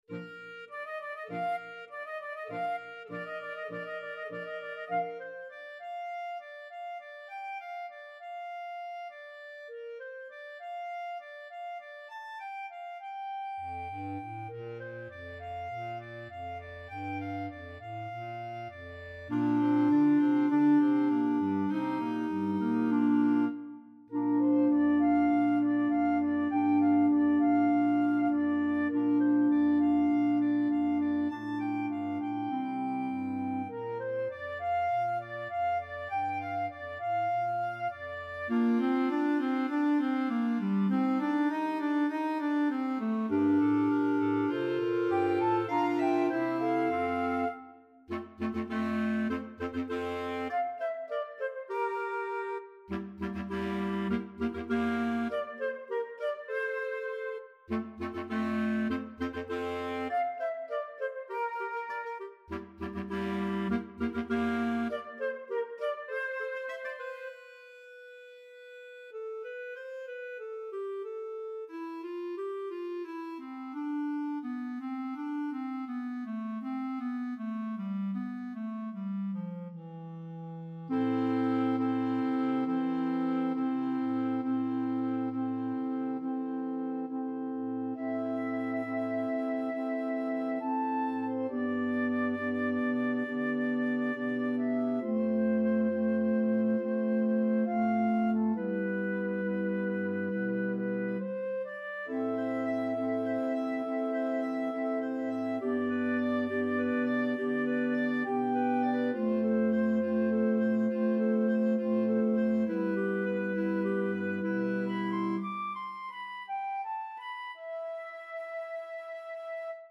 is a flute/clarinet duet accompanied by a clarinet quintet